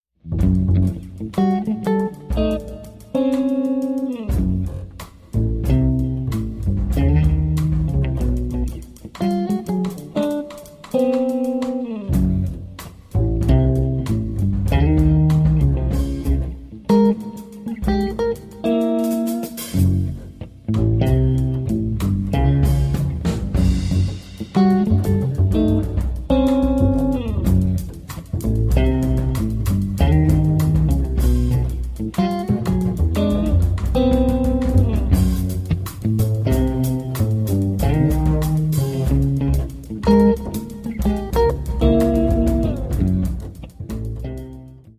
Contemporary Jazz Guitar meets Pitch Class Set Improvisation
Guitar
Bass
Drums